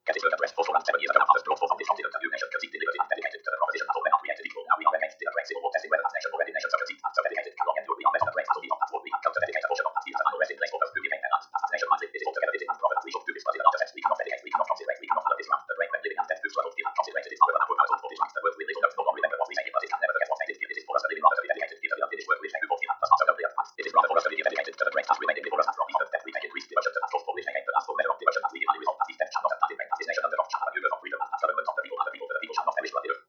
The exercise began with the robotic voice of a screen reader.
The voice of the screen reader was designed to be understood if it is sped up as the listener grows his perception.
Lincoln zipping along, and yes, with practice, I can make it out.